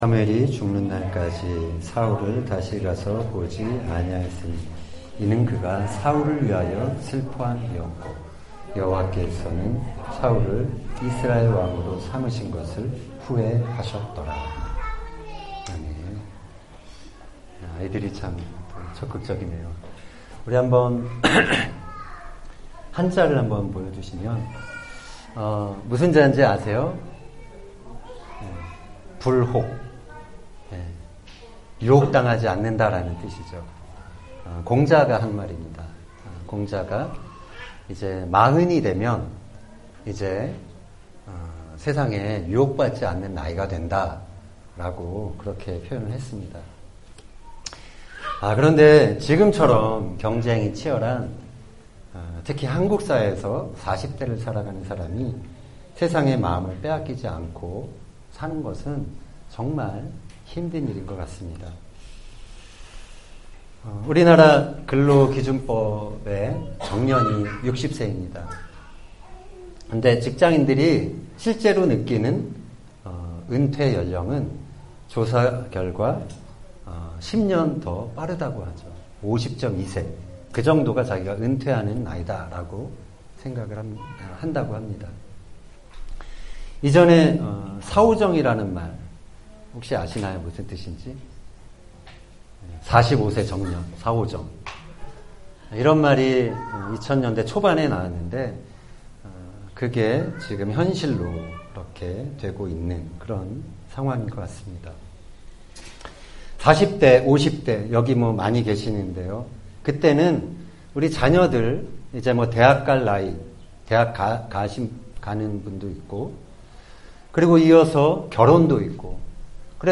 2018년 10월 6일 텔아비브 욥바교회 설교